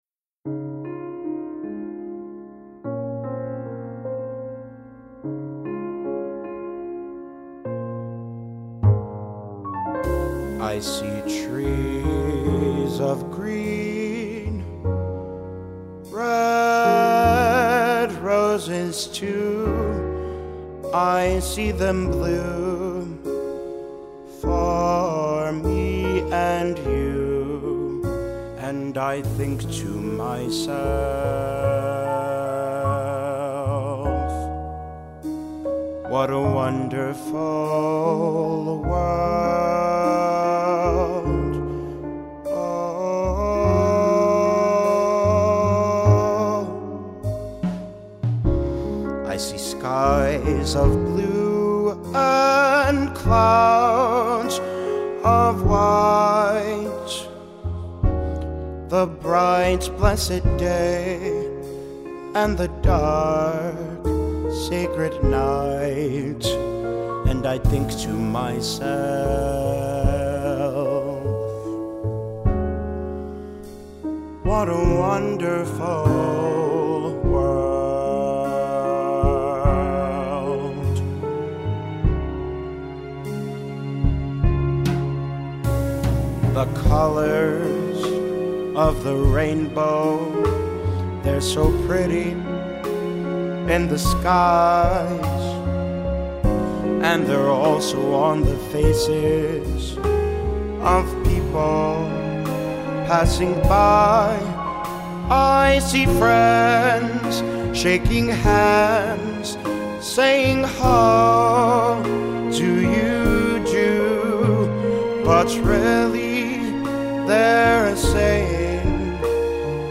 the clear beauty and sincerity of his voice